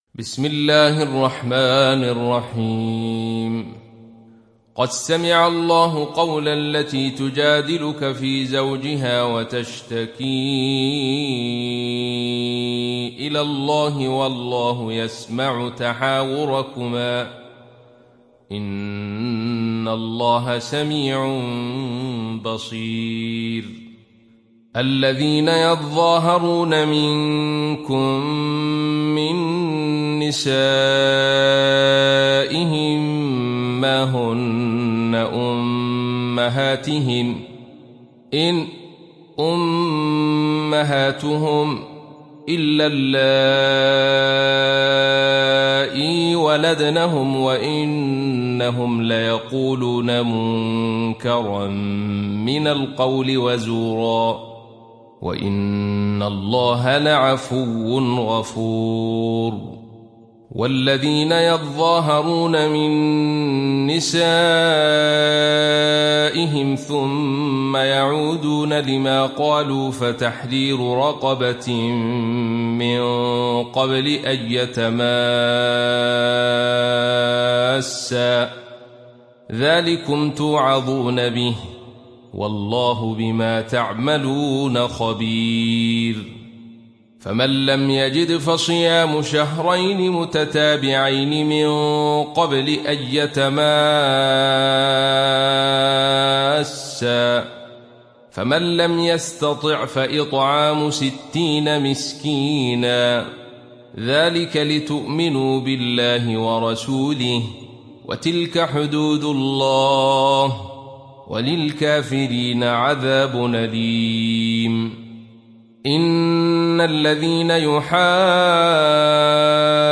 تحميل سورة المجادلة mp3 بصوت عبد الرشيد صوفي برواية خلف عن حمزة, تحميل استماع القرآن الكريم على الجوال mp3 كاملا بروابط مباشرة وسريعة